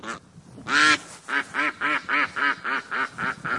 quack.ogg